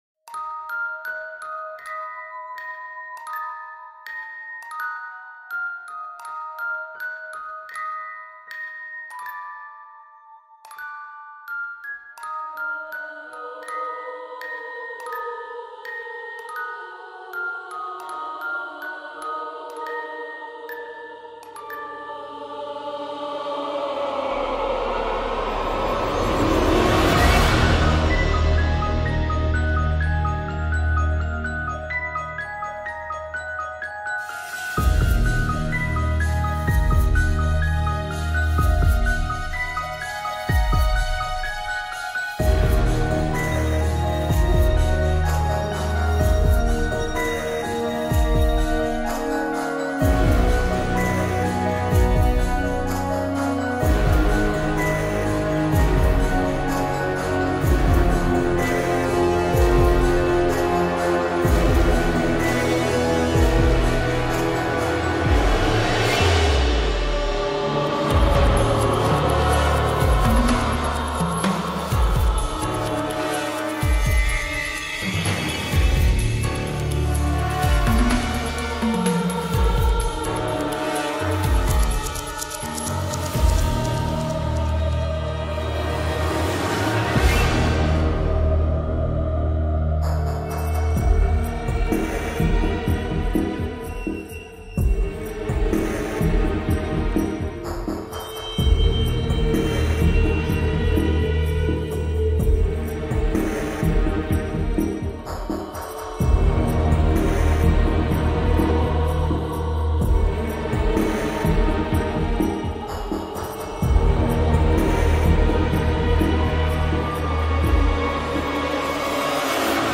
آهنگ بی کلام ترسناک